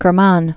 (kər-män, kĕr-)